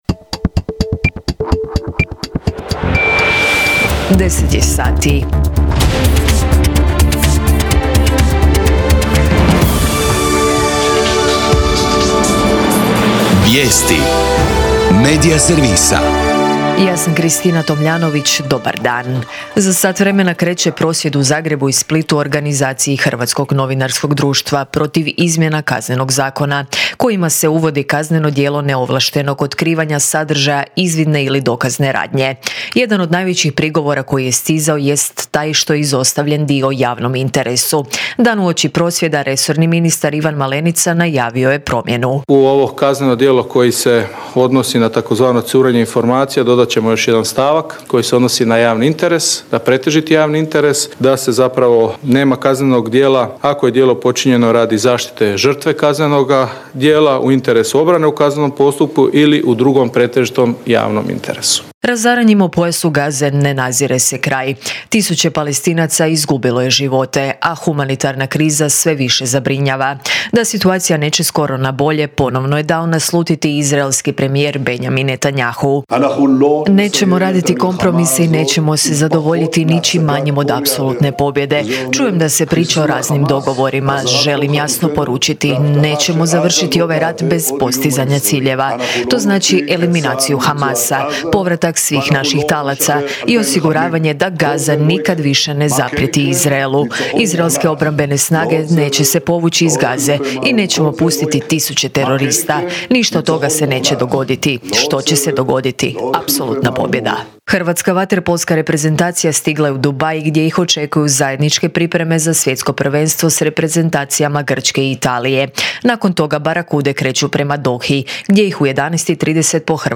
VIJESTI U 10